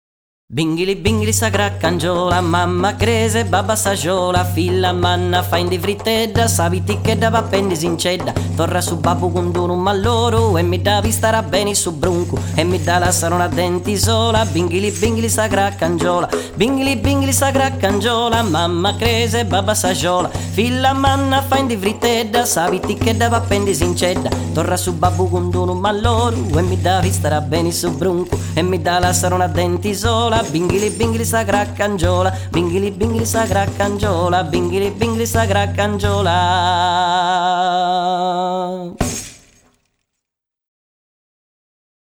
boxi / voce / voice
percussioni / percussione / percussion
Filastrocca popolare campidanese che gioca con il rumore prodotto dal pedale del telaio, con le tipiche frasi nonsense e il tradizionale uso della rima, oltre alla cadenza ritmica normale espressione dei componimenti per l’infanzia.
A popular Campidanese nursery rhyme that plays with the noise produced by the loom pedal. The typical nonsense phrases and the traditional use of rhyme, along with the rhythmic cadence, are regular features of childrens songs.